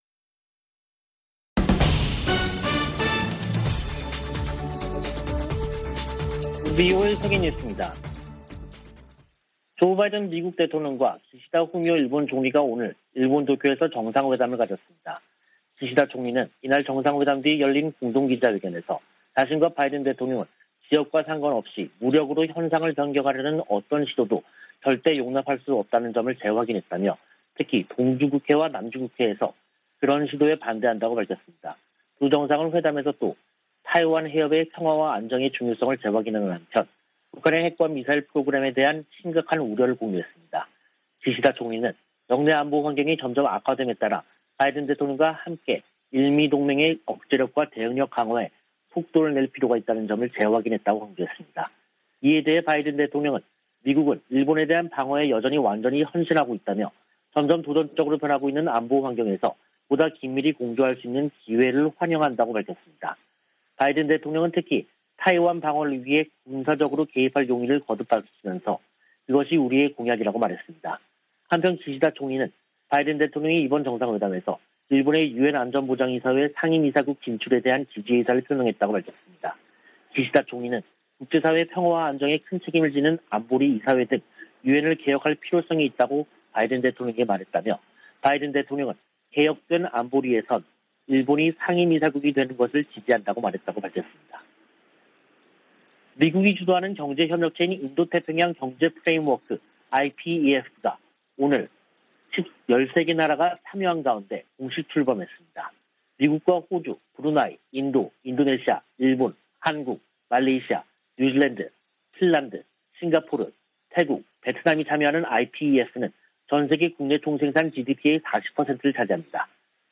VOA 한국어 간판 뉴스 프로그램 '뉴스 투데이', 2022년 5월 23일 3부 방송입니다. 조 바이든 미국 대통령과 기시다 후미오 일본 총리는 북한의 핵과 탄도미사일 프로그램을 규탄했습니다. 바이든 대통령이 미국을 포함한 13개 국가가 참여하는 '인도태평양 경제프레임워크(IPEF)' 출범을 공식 선언했습니다. 워싱턴의 전문가들은 미한동맹이 바이든 대통령의 방한을 계기로 안보동맹에서 기술안보, 글로벌 동맹으로 진화했음을 보여줬다고 평가했습니다.